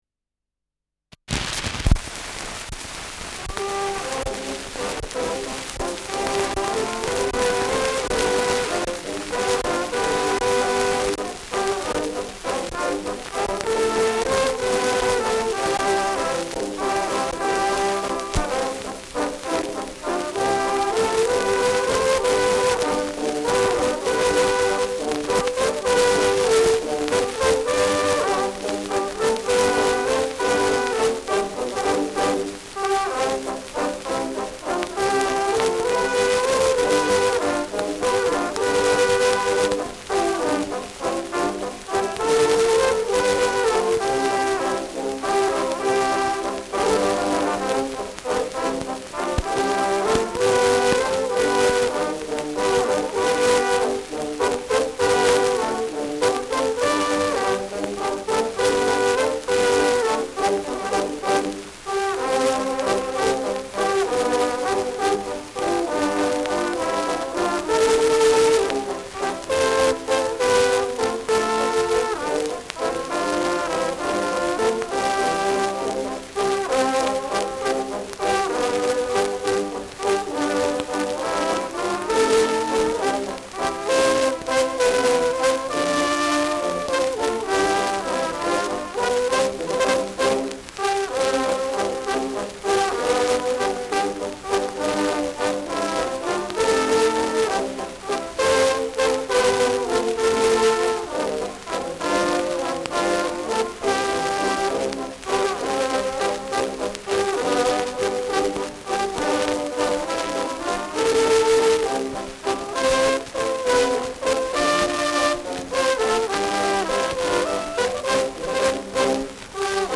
Schellackplatte
Starkes Grundrauschen : Zahlreiche Aussetzer zu Beginn : Verzerrt an lauteren Stellen : Gelegentlich leichtes bis stärkeres Knacken